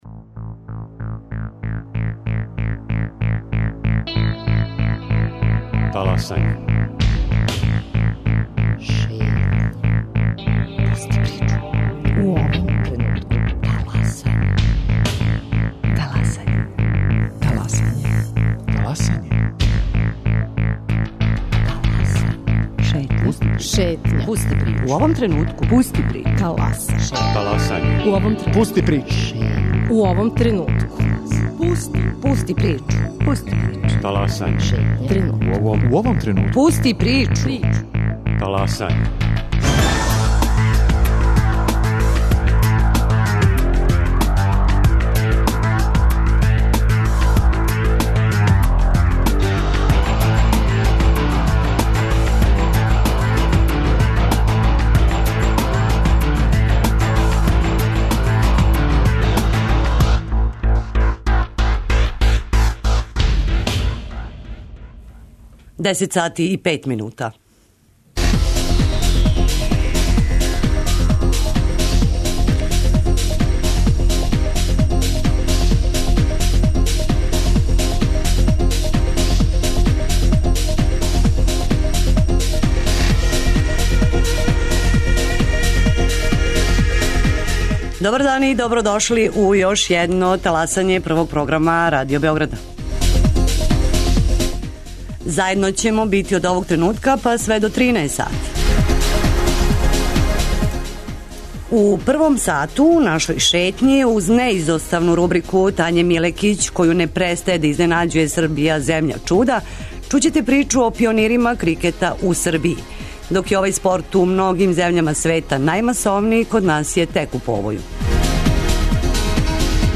Како су дошли на идеју да формирају крикет тим, какве резултате постижу и ко им помаже, чућете од чланова репрезентације Србије у крикету.